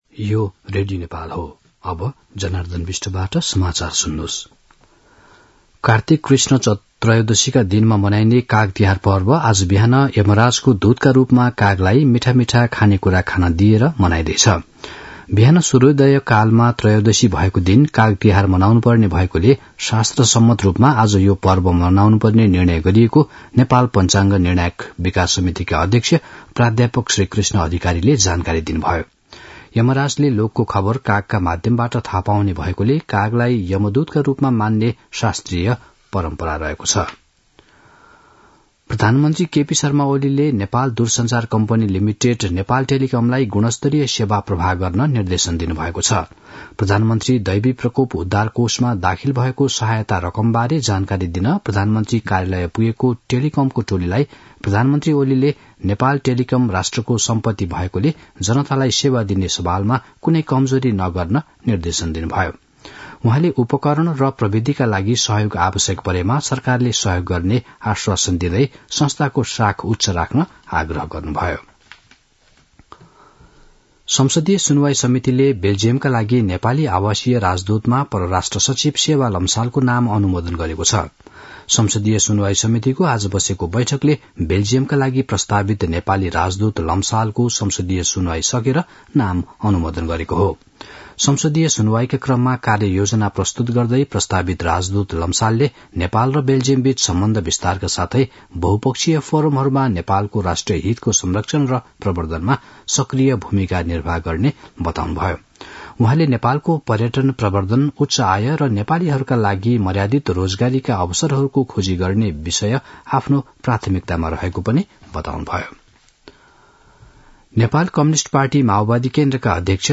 दिउँसो १ बजेको नेपाली समाचार : १४ कार्तिक , २०८१